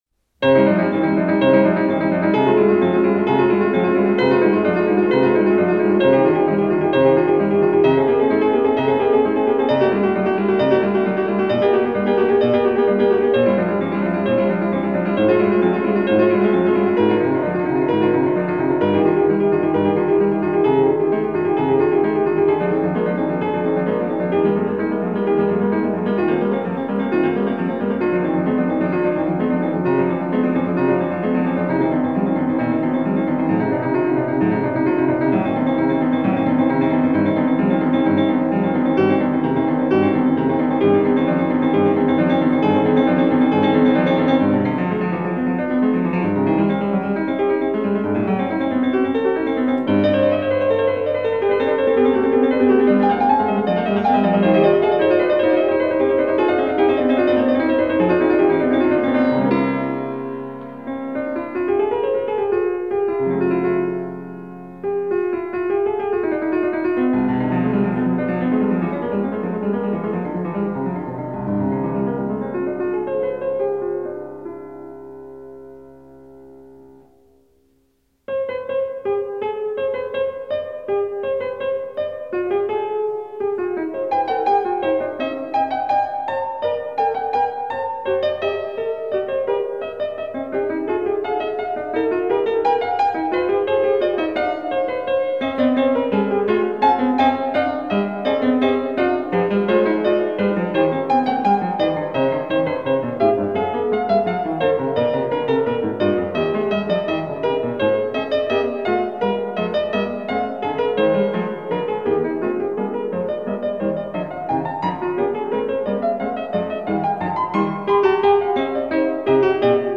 Две вещи– Prelude No.2 in C minor, BMV 847 и Fugue No.2 in C minor BWV 848 .